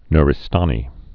(nrĭ-stänē)